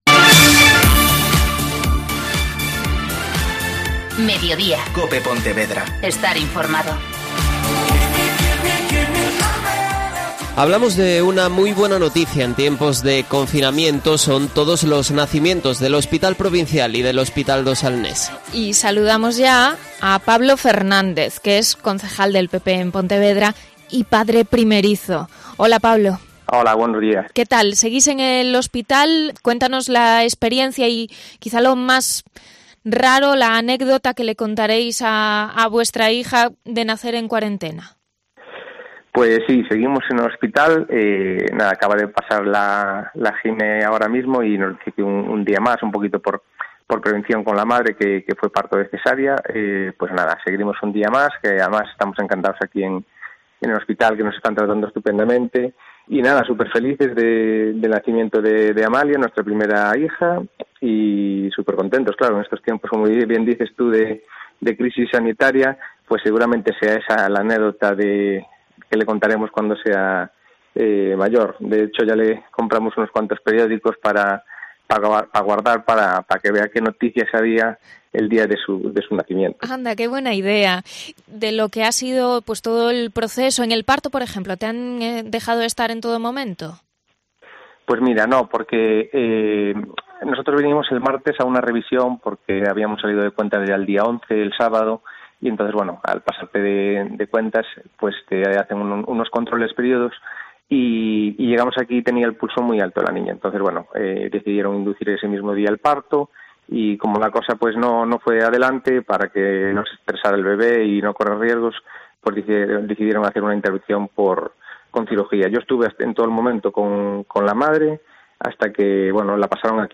Entrevista a unos padres primerizos en cuarentena